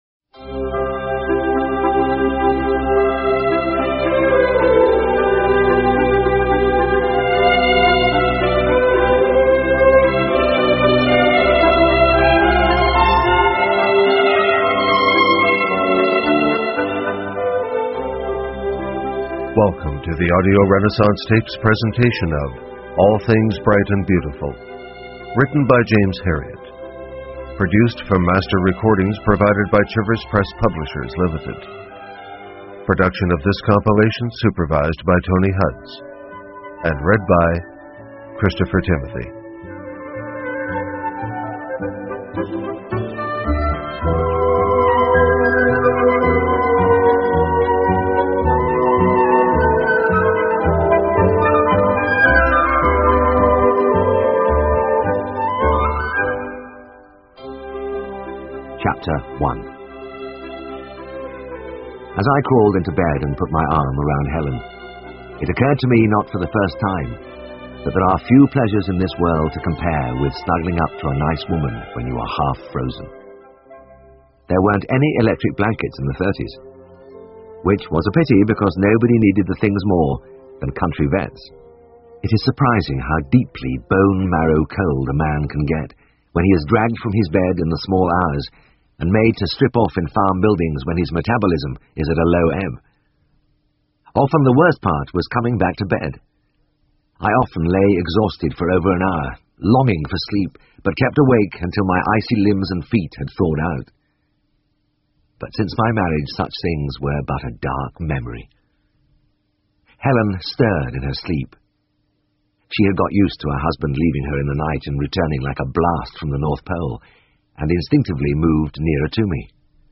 英文广播剧在线听 All Things Bright and Beautiful 1 听力文件下载—在线英语听力室